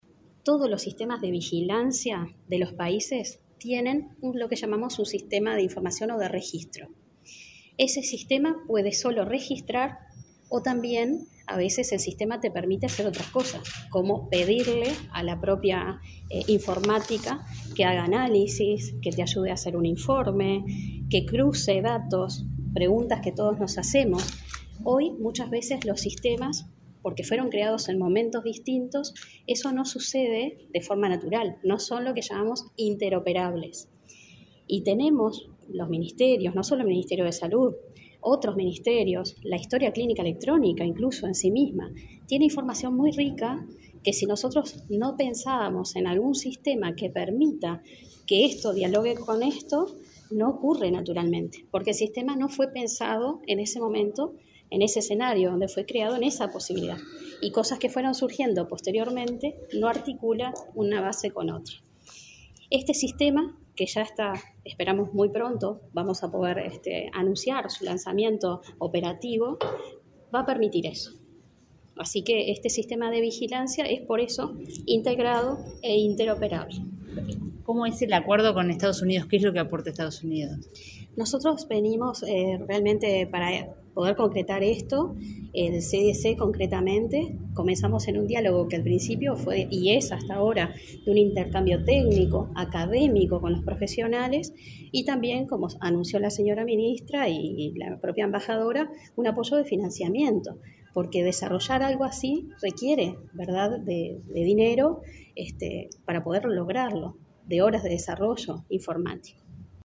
Declaraciones de la directora general de Salud, Adriana Alfonso
El Ministerio de Salud Pública (MSP) y la Embajada de Estados Unidos en Uruguay lanzaron una plataforma de cooperación entre ambas instituciones, con fondos de los centros para el control de enfermedades (CDC). Luego del acto, la directora general de Salud, Adriana Alfonso, dialogó con la prensa, acerca del alcance del Sistema Integrado de Vigilancia Epidemiológica.